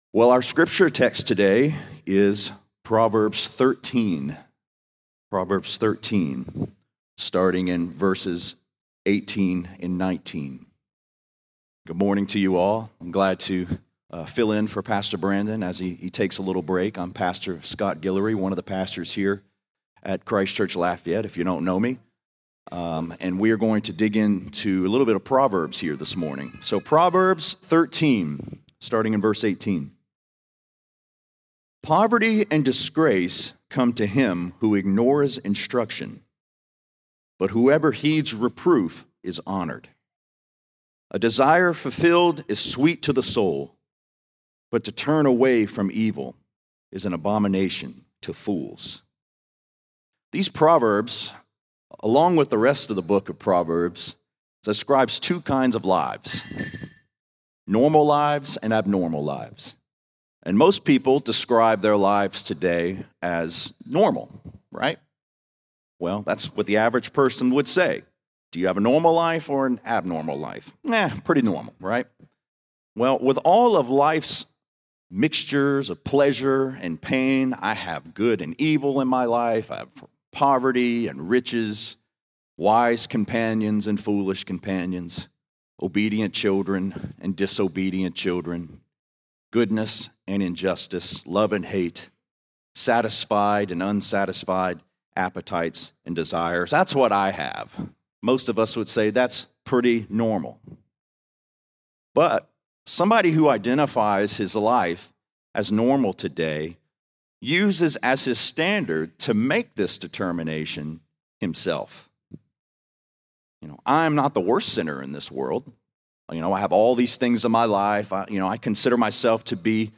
The Normal and Abnormal Life | Lafayette - Sermon (Proverbs 13)